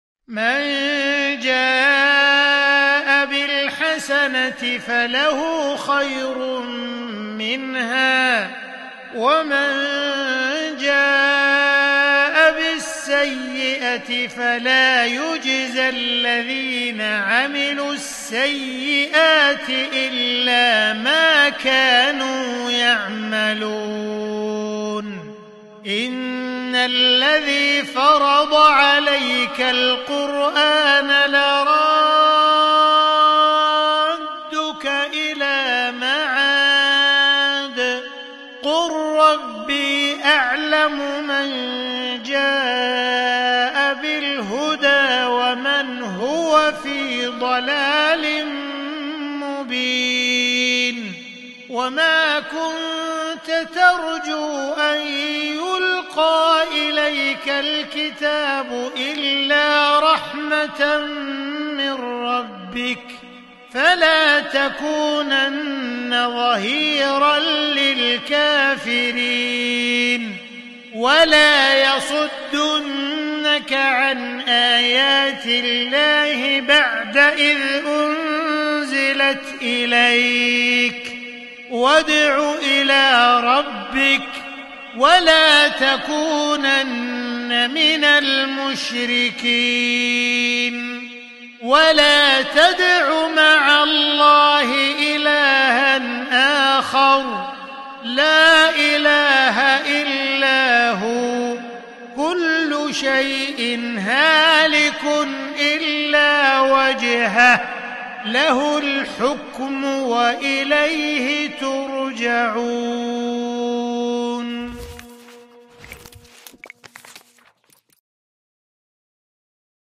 مقتطف من سورة القصص لمصحف الشيخ عبدالرحمن السديس الجديد ( تسجيل استديو ) > مصحف الشيخ عبدالرحمن السديس ( تسجيل استديو رئاسة الحرمين ) > المصحف - تلاوات الحرمين